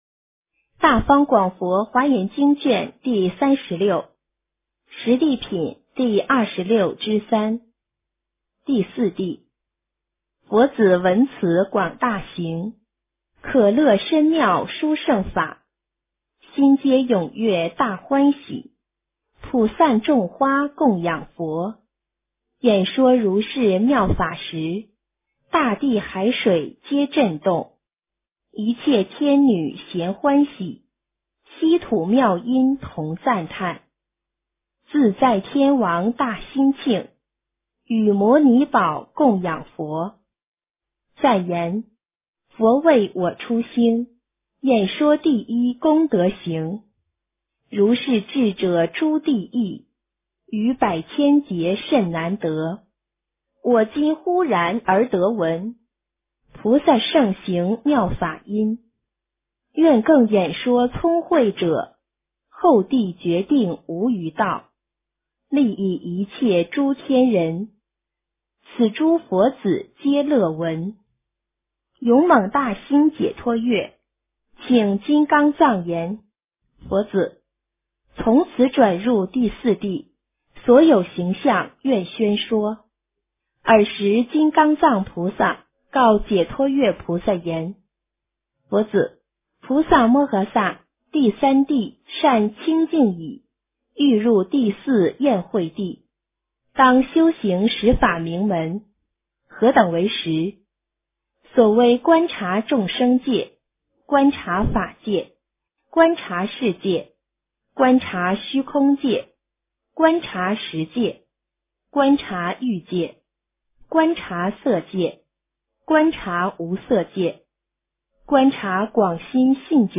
诵经
佛音 诵经 佛教音乐 返回列表 上一篇： 华严经31 下一篇： 华严经37 相关文章 心游太玄--巫娜 心游太玄--巫娜...